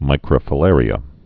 (mīkrə-fə-lârē-ə)